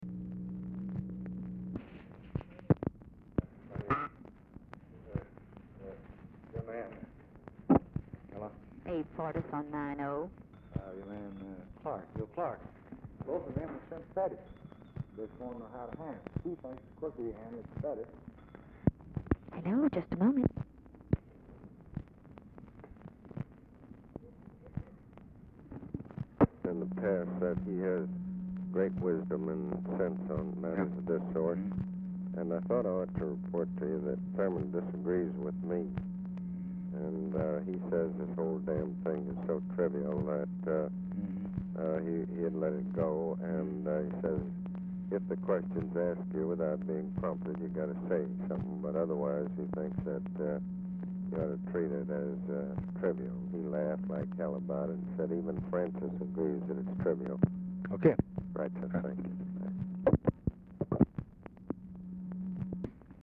White House Telephone Recordings and Transcripts
Oval Office or unknown location
Telephone conversation
Dictation belt